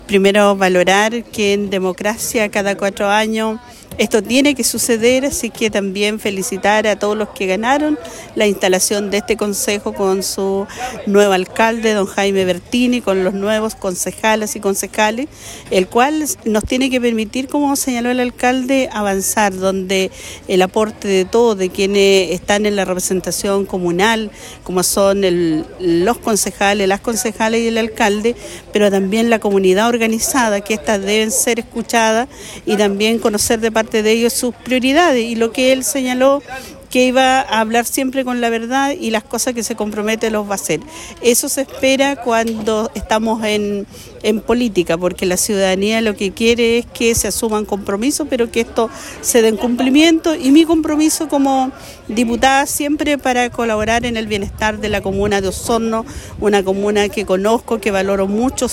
El pasado viernes, diversas autoridades nacionales y regionales participaron en la ceremonia de instalación del nuevo gobierno comunal de Osorno, encabezado por el Alcalde Jaime Bertín y su Concejo Municipal.